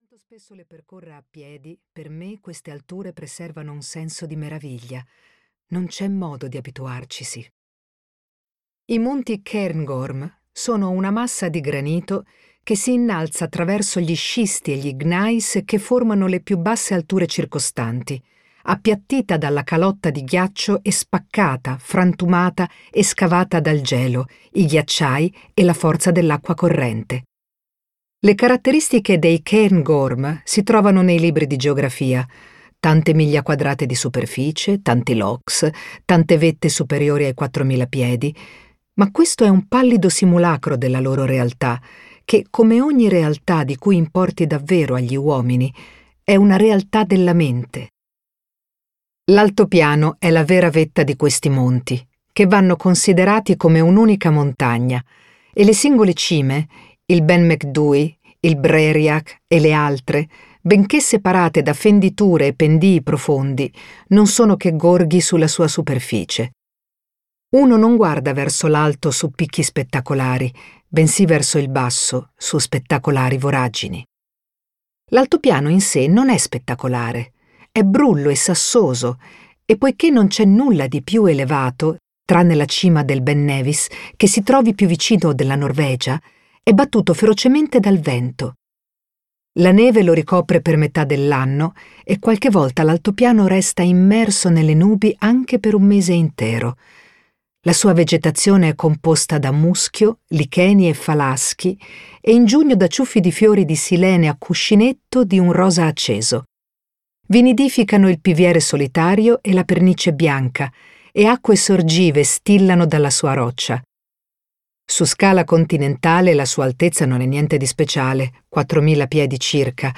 "La montagna vivente" di Nan Shepherd - Audiolibro digitale - AUDIOLIBRI LIQUIDI - Il Libraio